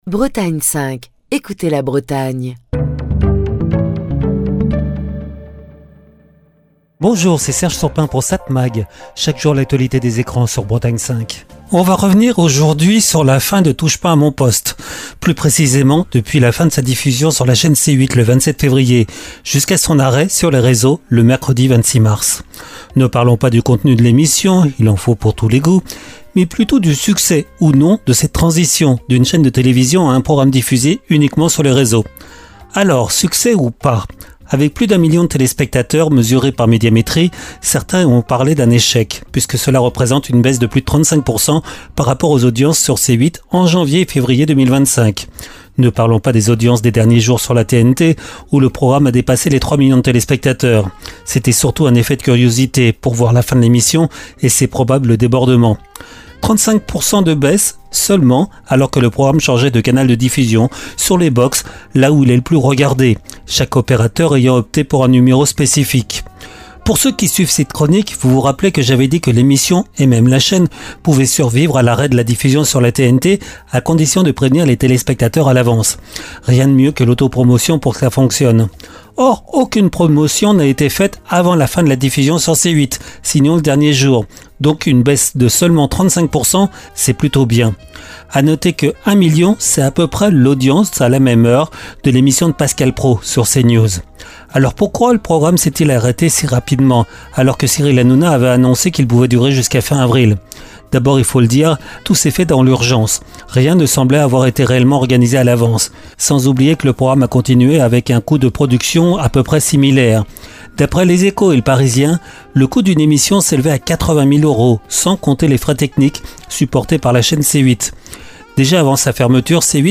Chronique du 31 mars 2025.